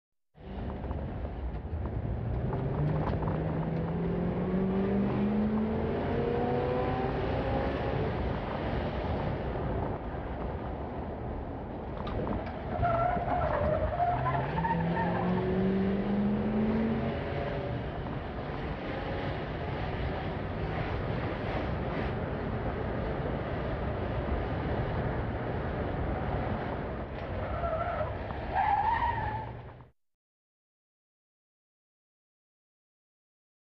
CarInteriorAccel BT032201
Car Interior - Accelerate, Squealing Tires, Maneuvers